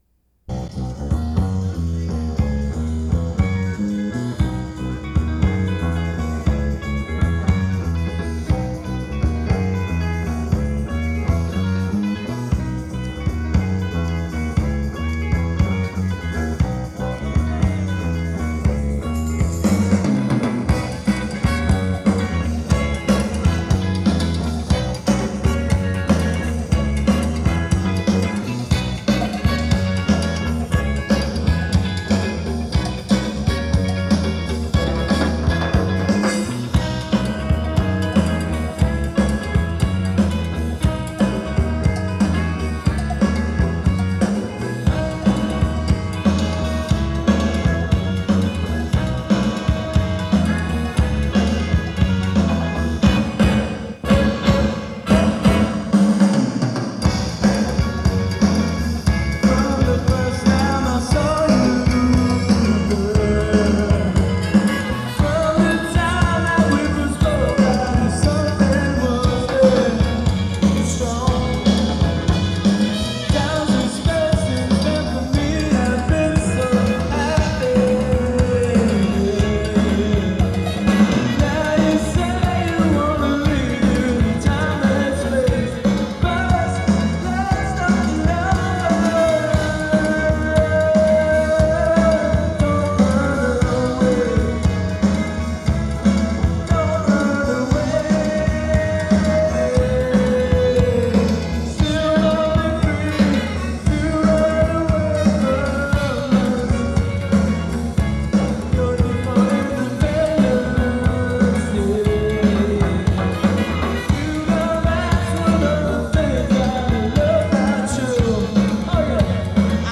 Live Tapes